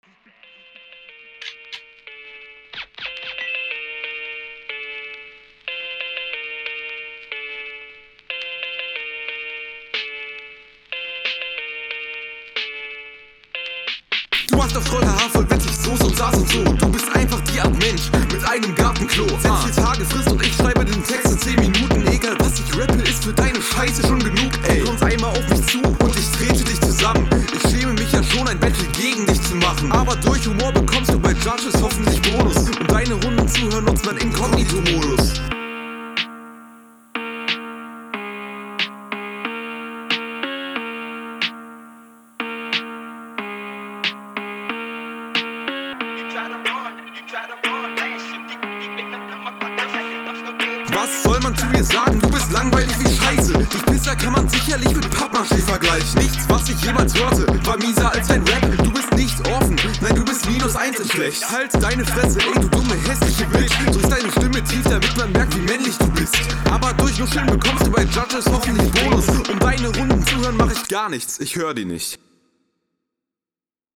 Flow: Flow ist recht vernuschelt, aber nette Ansätze sind dabei.
Man hört dich deutlich und es ist auf dem Beat.